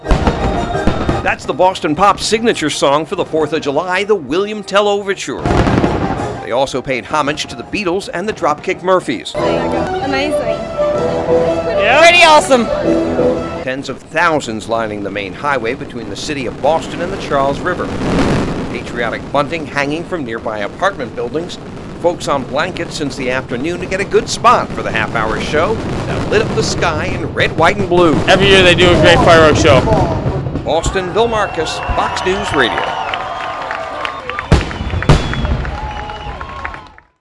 (BOSTON) JULY 4 – FIREWORKS WENT OFF FOR A HALF-HOUR ALONG THE CHARLES RIVER IN BOSTON TONIGHT.